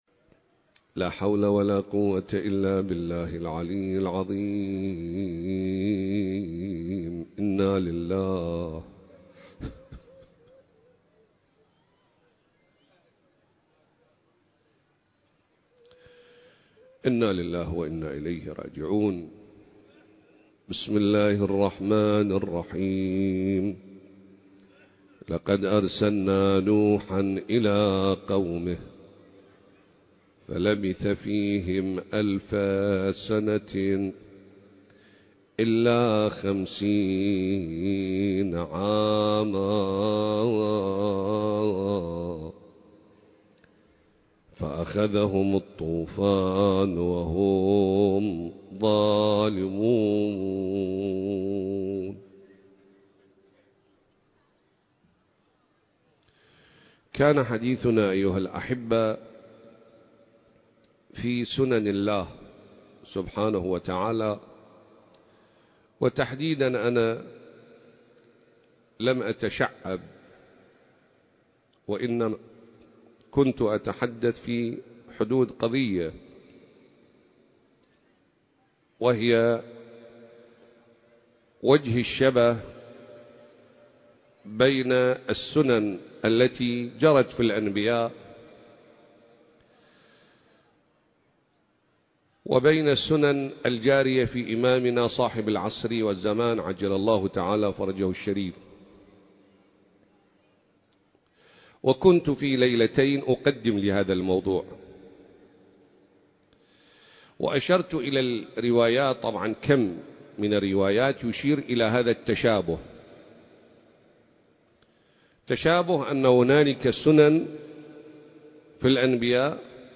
سلسلة: تشابه سنن الأنبياء (عليهم السلام) والإمام المهدي (عجّل الله فرجه) (3) المكان: العتبة العسكرية المقدسة التاريخ: 2024